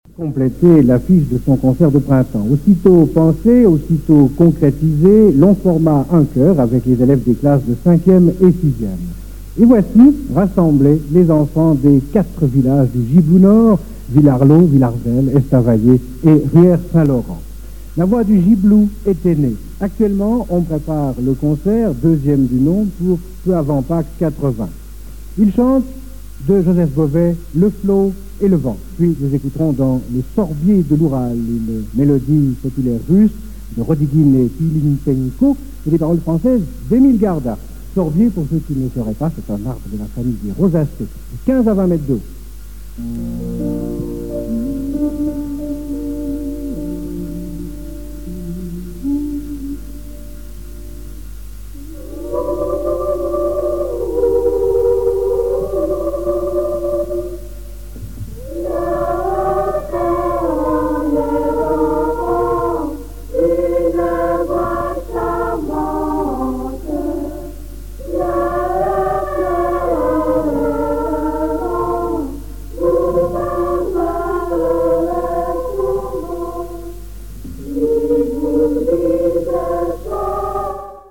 1977 - 1978 - Choeur d'enfants La Voix du Gibloux
Ils ont tous des voix de pinsons et ils aiment le chant.